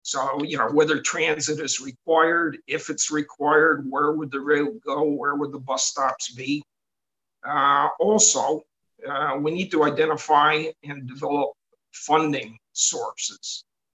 Councillor Bill Sandison told council Monday the last big transit review was done in 2015 and that the city has grown a lot since then and will continue to quickly grow.